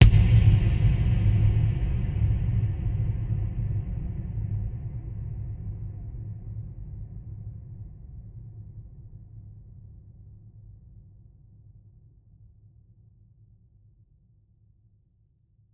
Big Drum Hit 04.wav